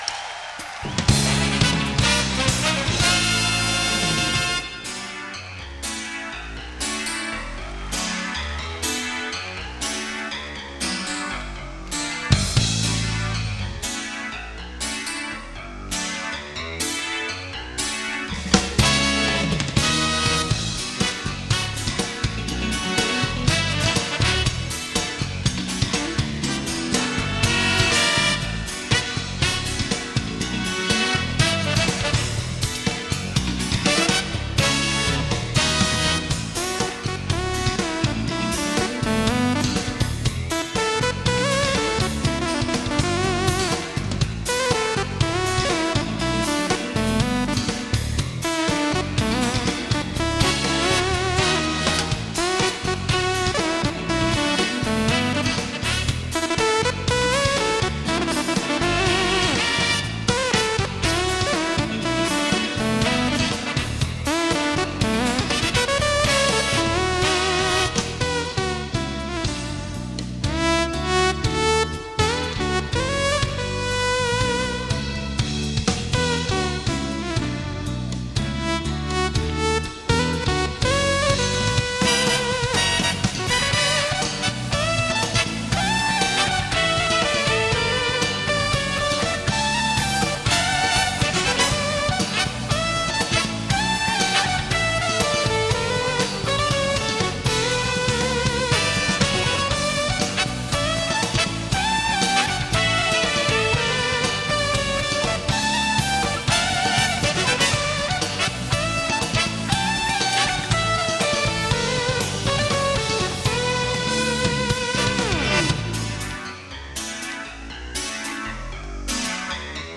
Live in Tokyo
EWI